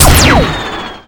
gun2.ogg